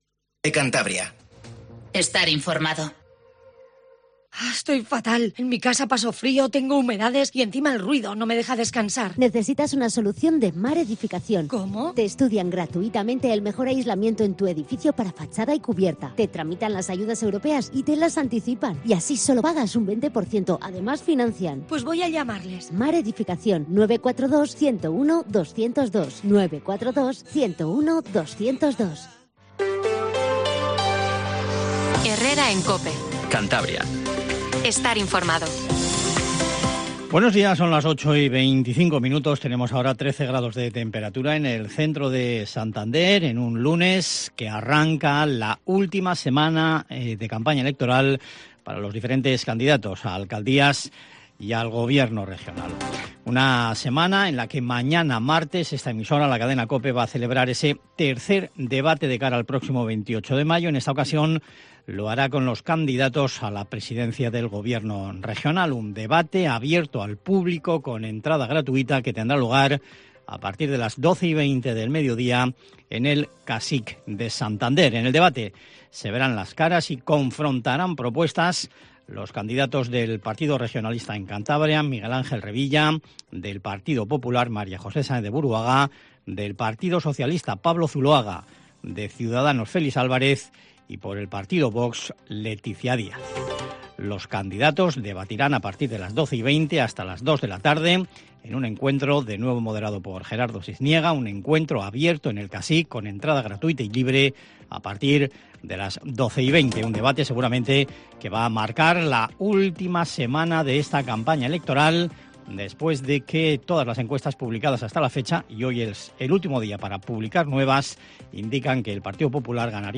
Informativo Matinal Cope 08:20